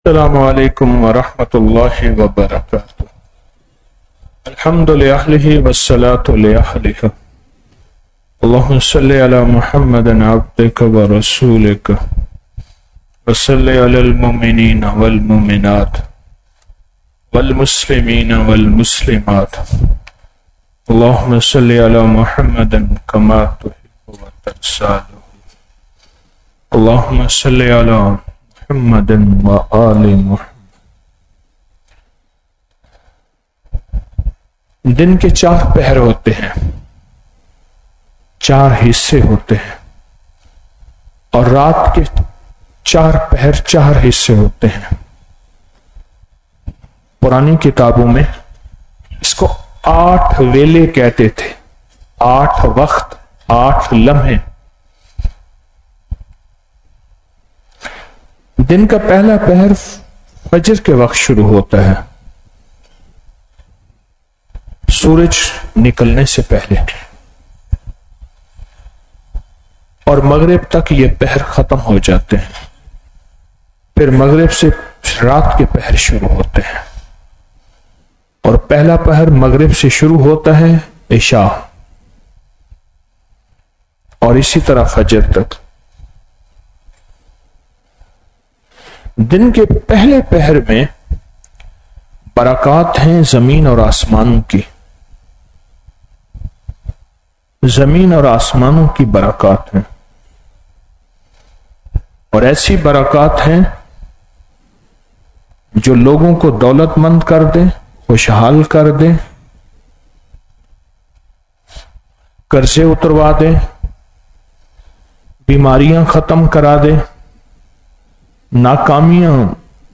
06 سوا کروڑ سورۃ الاخلاص |16 اکتوبر 2025 | شبِ جمعہ محفل